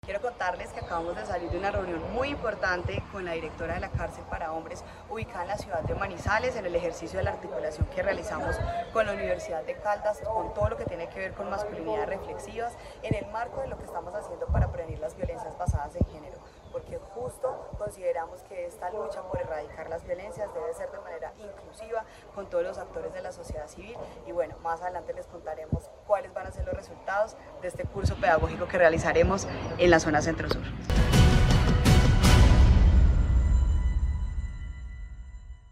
Audio Juanita Espeleta Noreña, secretaria de Integración y Desarrollo Social.